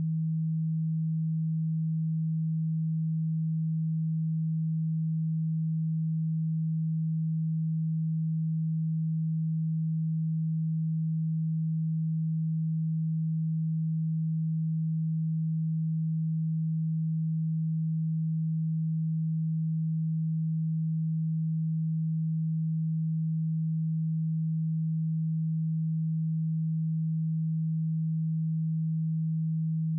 160Hz_-26.dB.wav